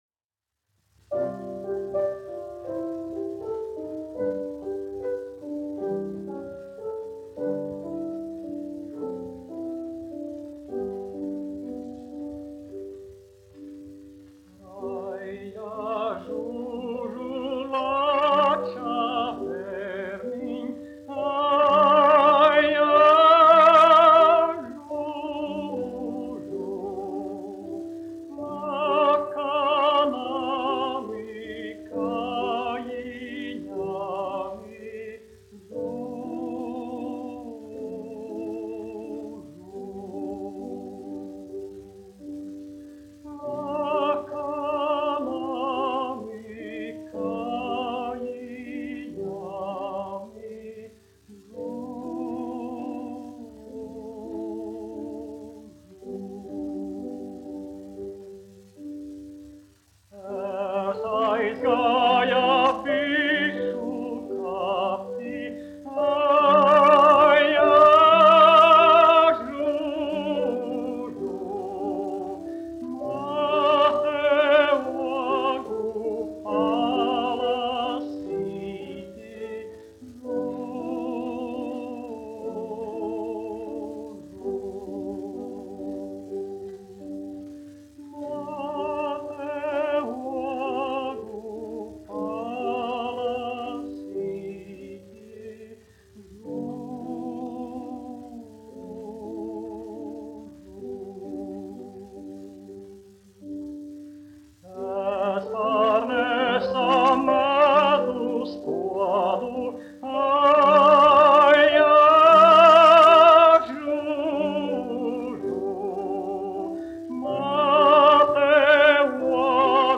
Jāzeps Vītols, 1863-1948, aranžētājs
1 skpl. : analogs, 78 apgr/min, mono ; 25 cm
Latviešu tautasdziesmas
Skaņuplate